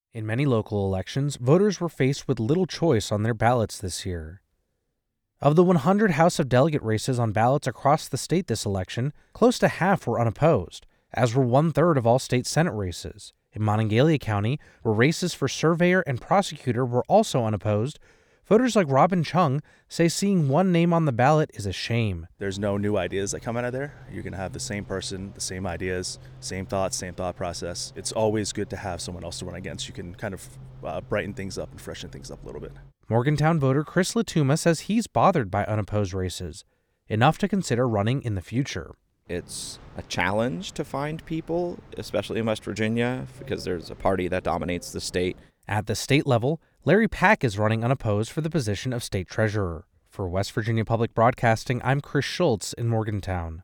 talks to Morgantown voters about unopposed races